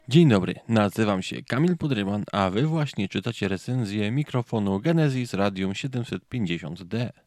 Radium 750D z kolei to model dynamiczny.
Mianowicie jest on o wiele bardziej odporny na zbieranie hałasów i szumów z otoczenia, co okazało się dla mnie nieocenioną zaletą. Poniżej zaś znajdziecie próbkę dźwięku nagraną właśnie tym mikrofonem: